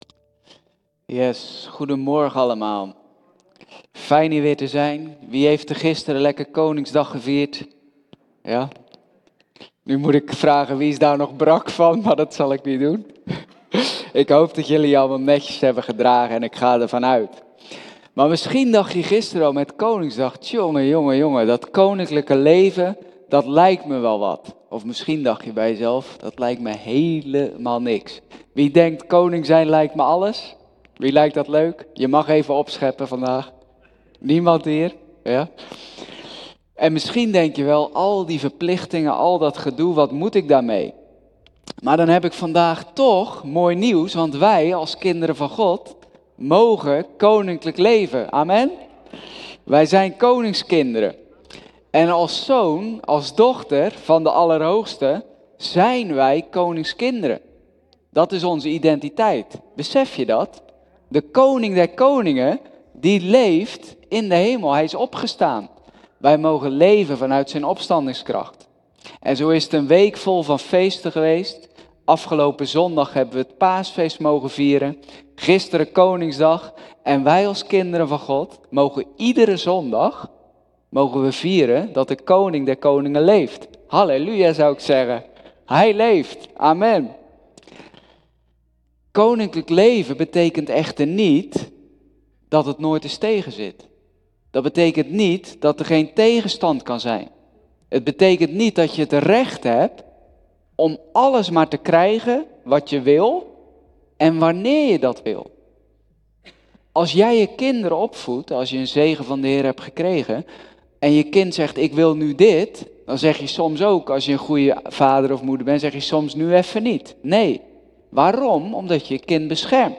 In zijn preek